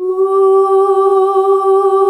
UUUUH   G.wav